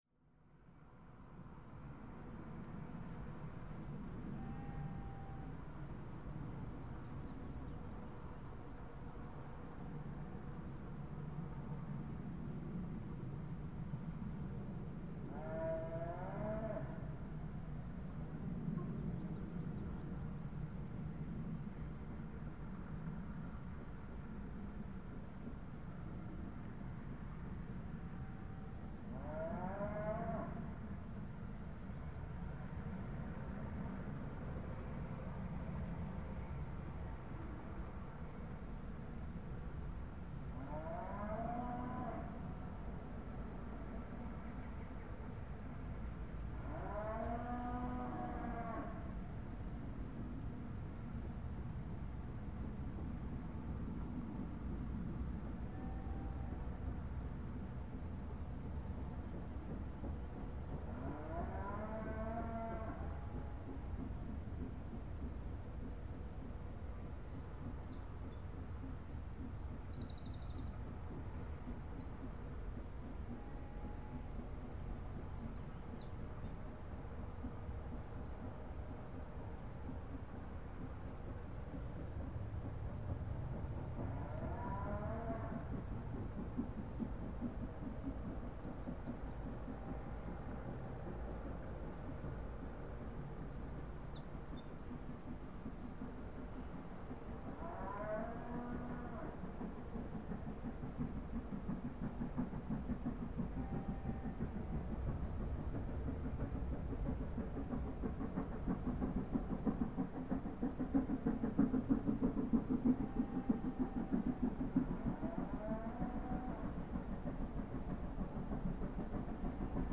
Am lautesten sind natürlich immer die Bergfahrten der „Black-Fives“ und dieser hier ganz besonders.
44767 knallt regel(ge)recht bei Green End unter der Brücke durch, so dass es schon in den Ohren schmerzte, am 08.08.2000 um 10:57h.